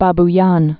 (bäb-yän)